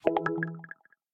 match-confirm.ogg